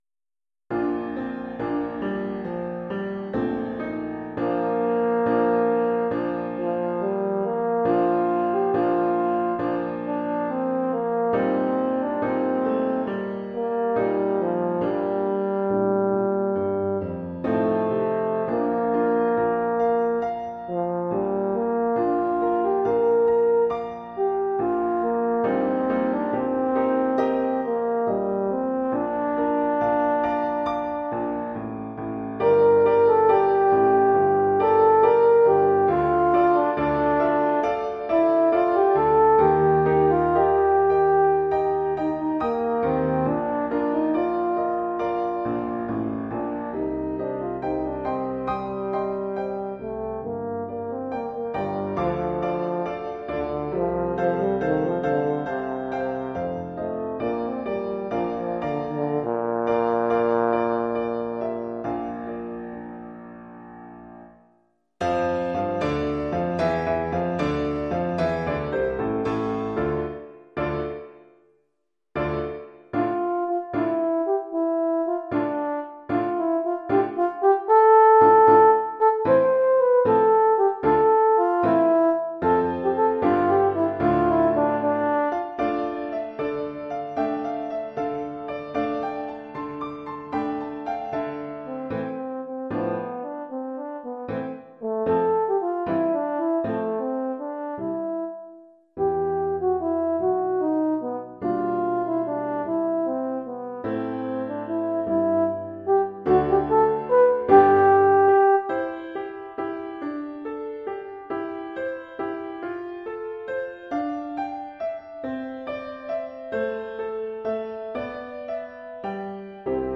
Oeuvre pour cor d’harmonie et piano.